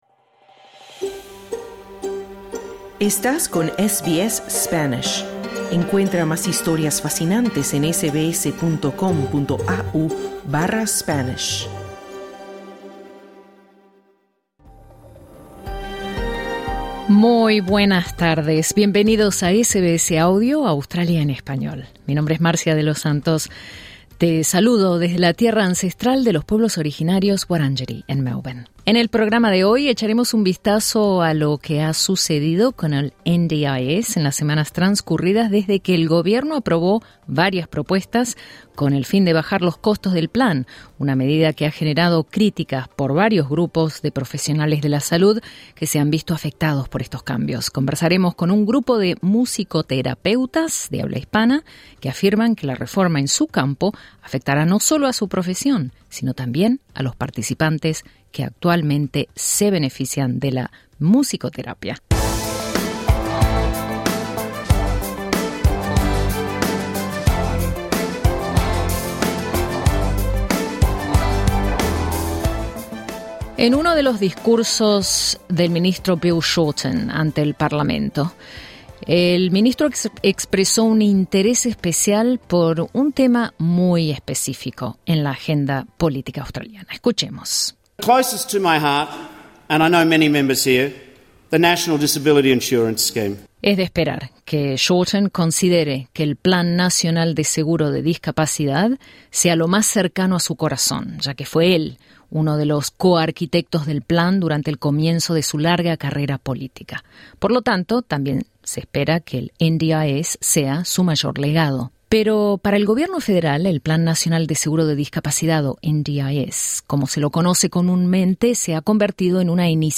Conversamos con ellos sobre las razones por las que piden que los cambios sean archivados.